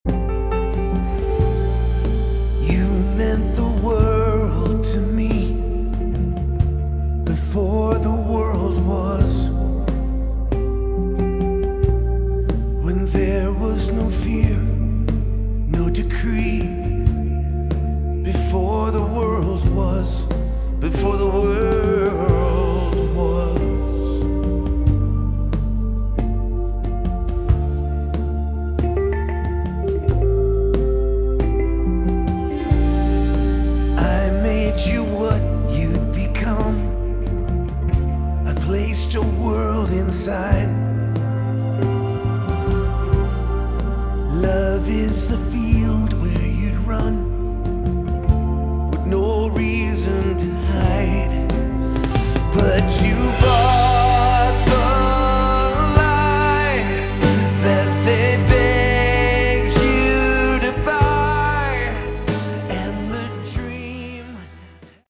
Category: Prog Rock
guitars